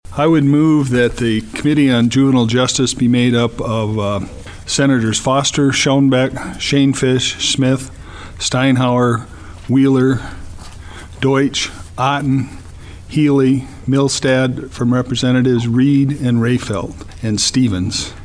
Representative Hugh Bartels of Watertown lists the members of the Study Committee on Juvenile Justice.